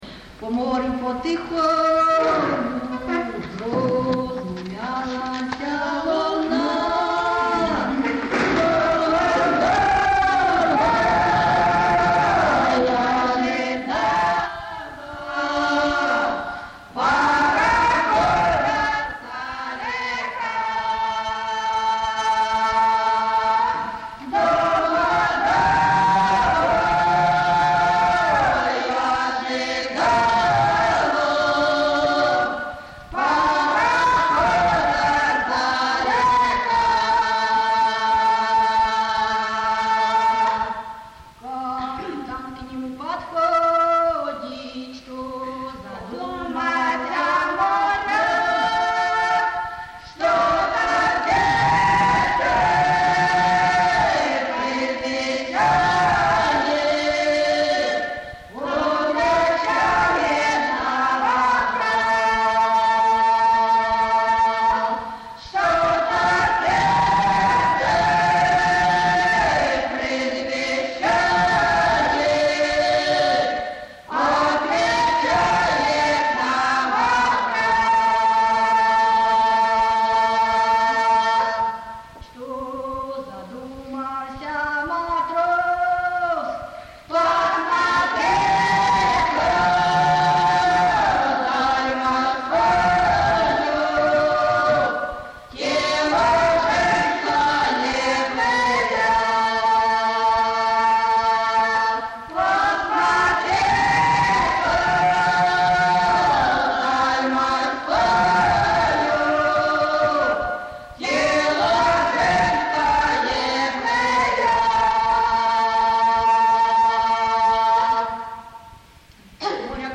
ЖанрПісні з особистого та родинного життя, Балади, Сучасні пісні та новотвори, Моряцькі
Місце записус-ще Зоря, Краматорський район, Донецька обл., Україна, Слобожанщина